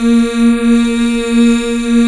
Index of /90_sSampleCDs/Club-50 - Foundations Roland/VOX_xFemale Ooz/VOX_xFm Ooz 1 M